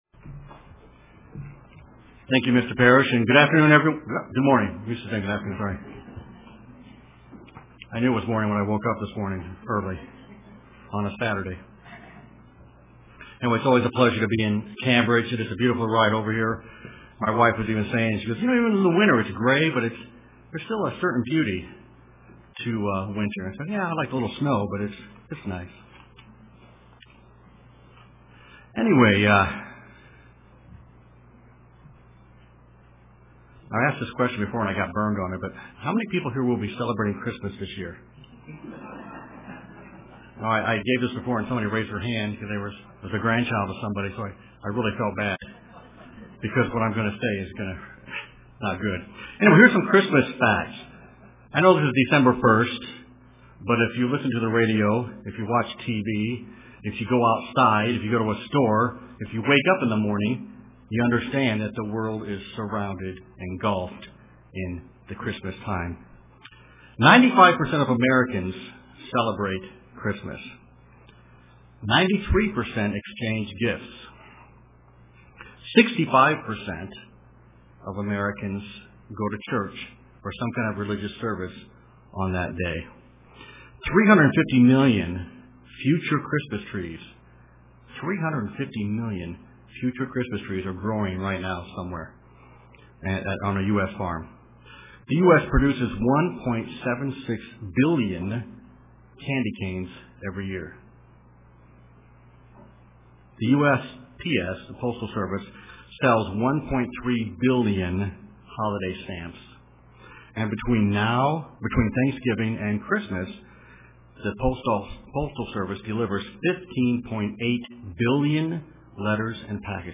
Print Is This Really the Most Wonderful Time of the Year UCG Sermon Studying the bible?